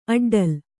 ♪ aḍḍal